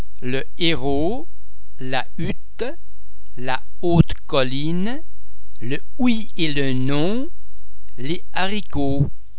Please be mindful of the fact that all the French sounds are produced with greater facial, throat and other phonatory muscle tension than any English sound.
Isolated exceptions: the [e] or [a] of the preceding word is NOTdropped.